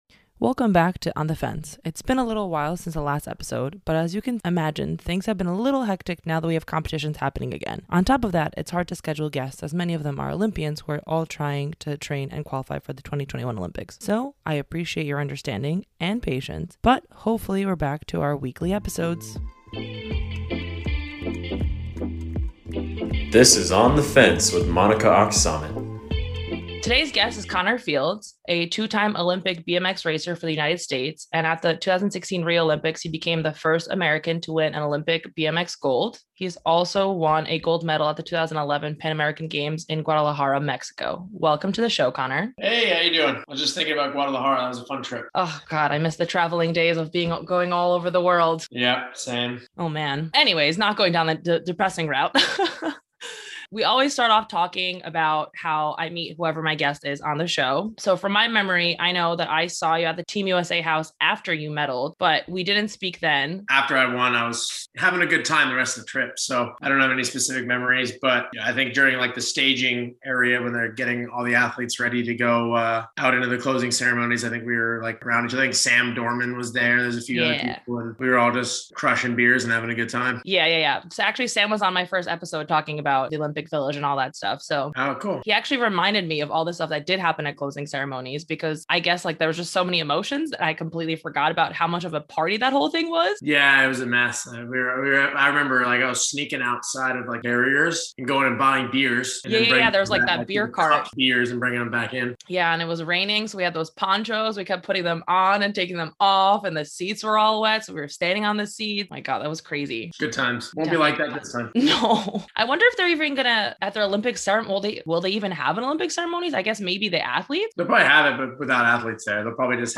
This time her guest is American BMX Olympic Gold Medalist, Connor Fields. The two sit down to explain what doping control looks like and the different versions that exist. They also share some funny stories, as well as some embarrassing ones!